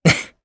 Evan-Vox_Happy1_jp.wav